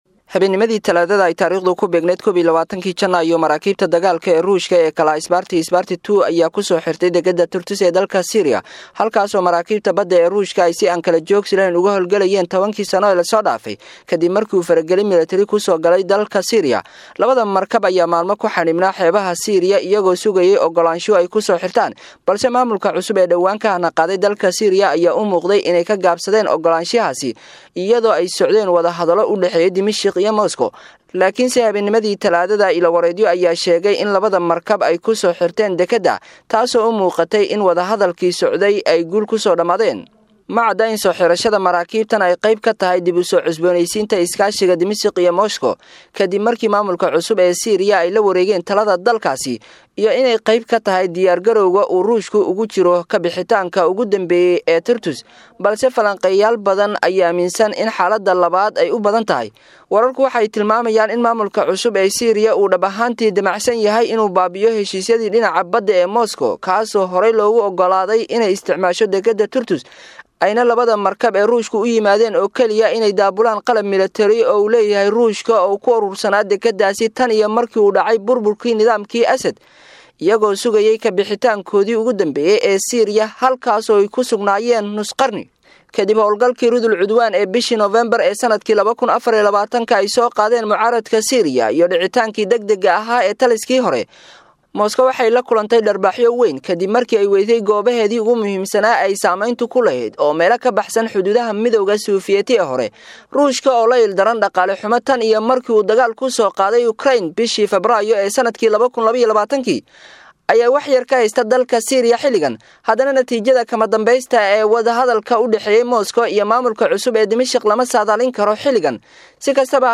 Warbixin_Kadib_Galbashadii_Nidaamkii_Nuseyriyada_Suuriya_Halkee.mp3